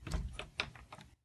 window-opening.wav